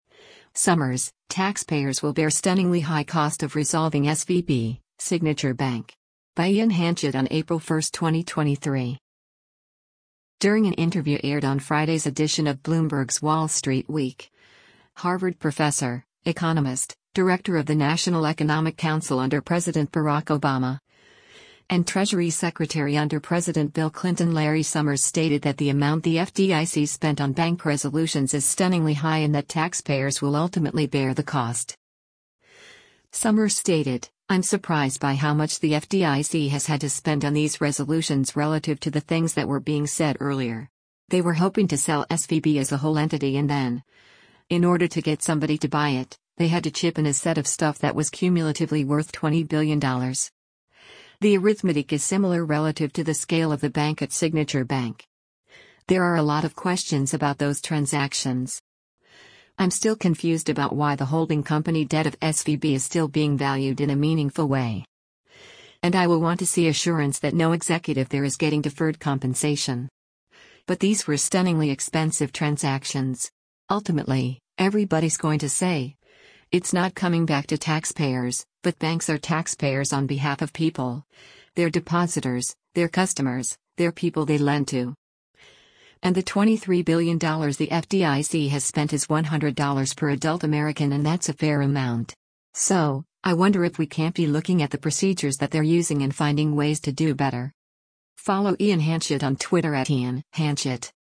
During an interview aired on Friday’s edition of Bloomberg’s “Wall Street Week,” Harvard Professor, economist, Director of the National Economic Council under President Barack Obama, and Treasury Secretary under President Bill Clinton Larry Summers stated that the amount the FDIC spent on bank resolutions is “stunningly” high and that taxpayers will ultimately bear the cost.